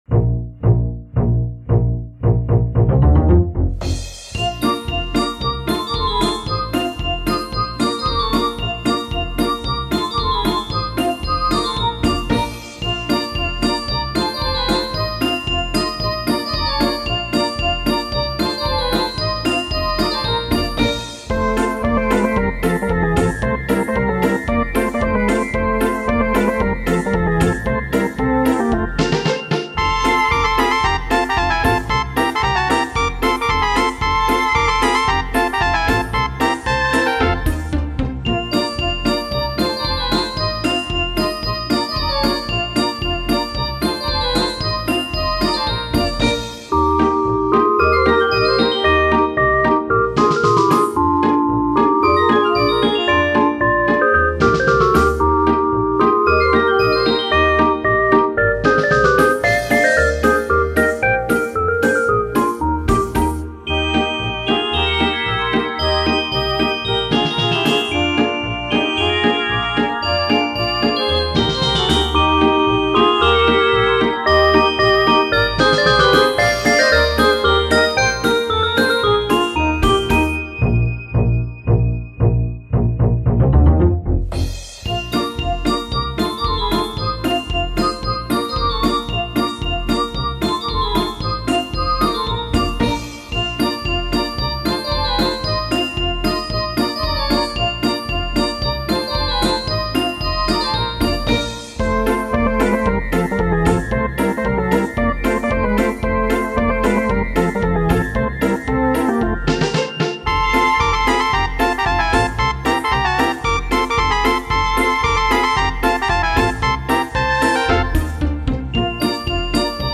6. Фізхвилинка (полька)
2.-полька.mp3